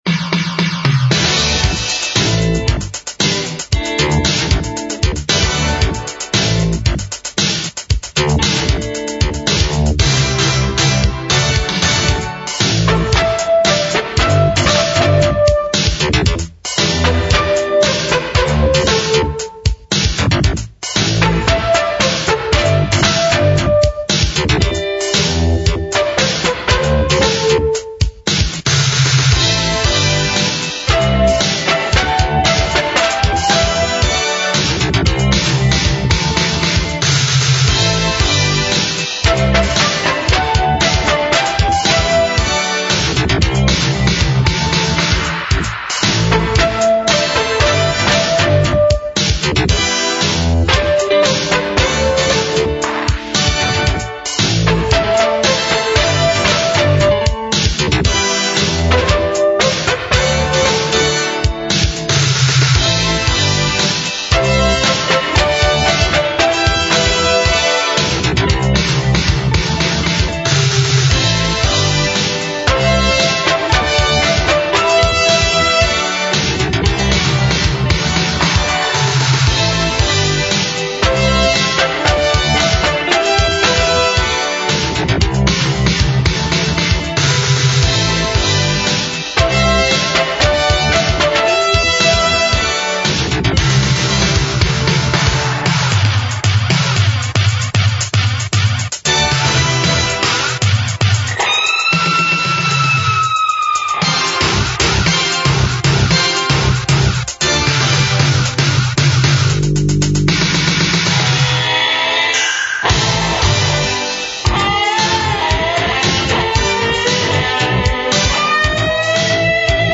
svižně, Beat, kytara, klávesy, sólo flétna